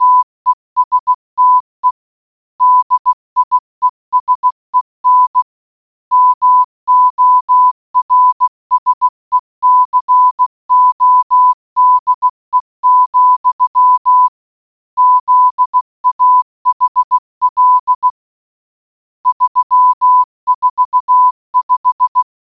morsecode.mp3